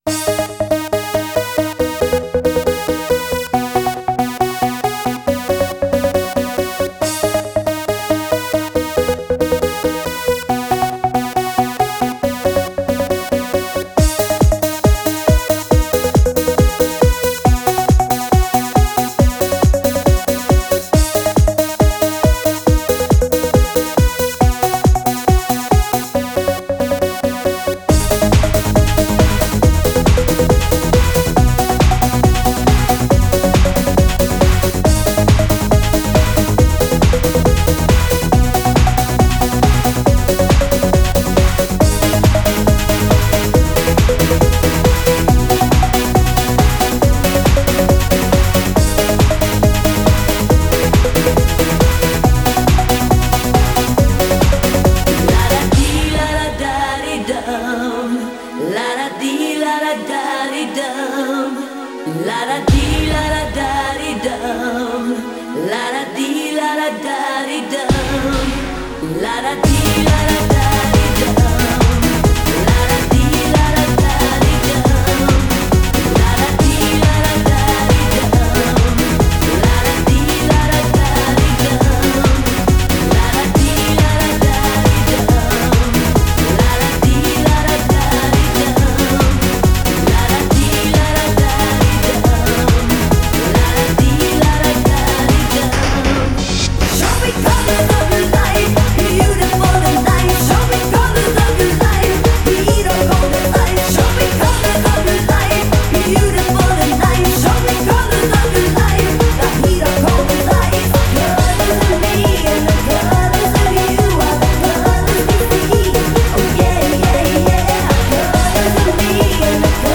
Genre: Eurodance.